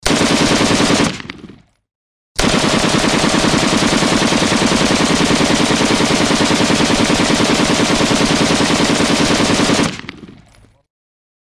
На этой странице собраны реалистичные звуки стрельбы из автомата в высоком качестве.
Грохот стрельбы на войне из калаша